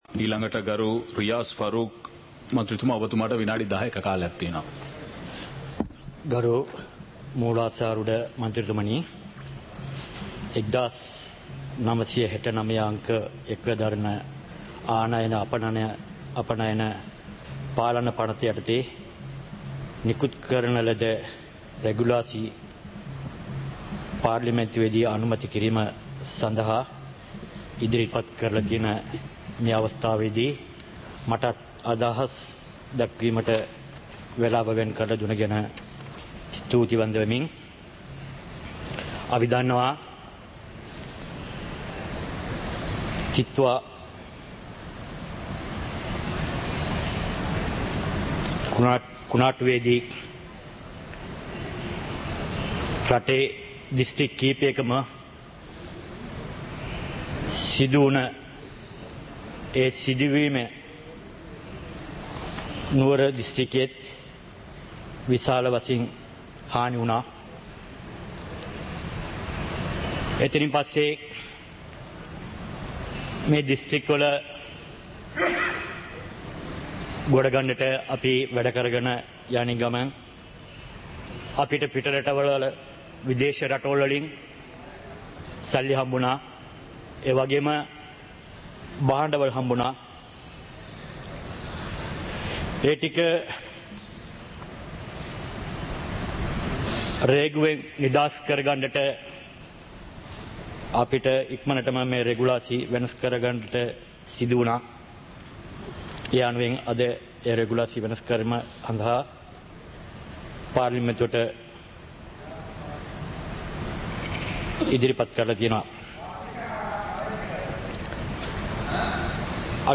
சபை நடவடிக்கைமுறை (2026-01-09)
நேரலை - பதிவுருத்தப்பட்ட